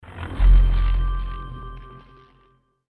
Plus it had a great eerie soundtrack. Here are a few sounds it would make when various options were selected:
sigil_sound.mp3